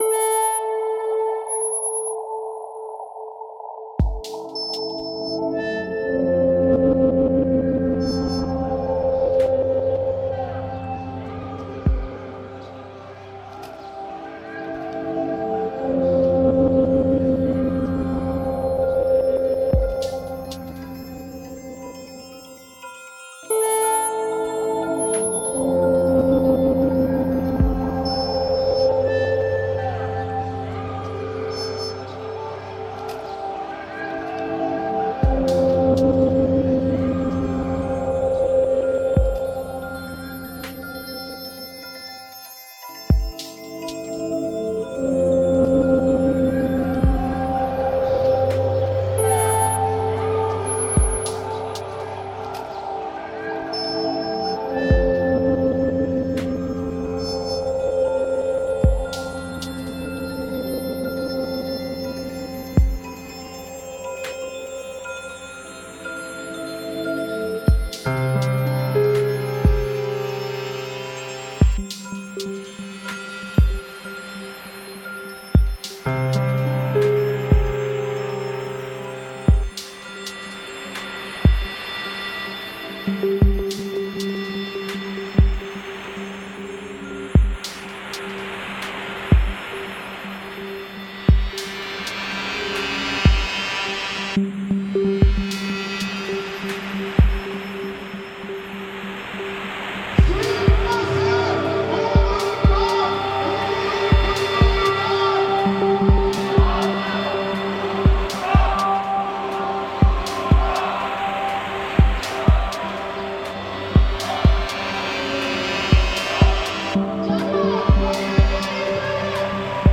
I reimagined the sounds in layers, altered and distorted, to reflect how protest is expressed in the layers of the individual: what we think, do, act, witness, hear and see.